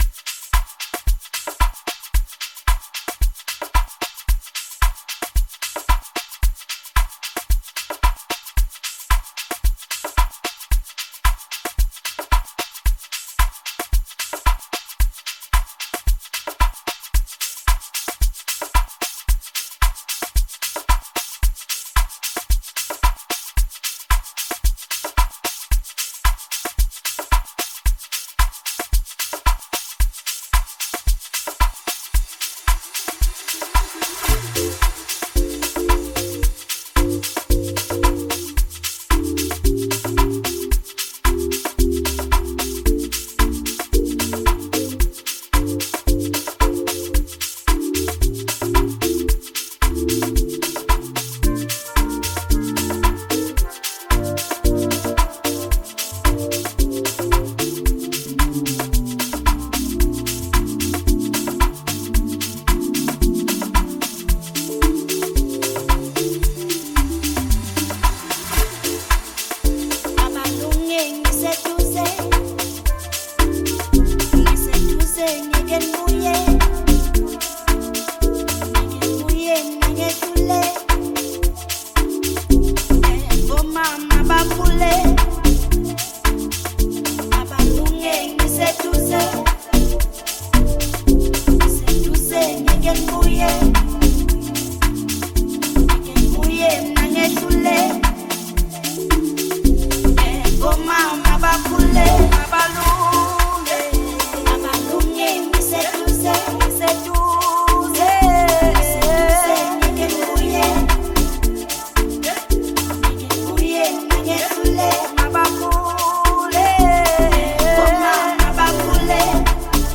Amapiano-infused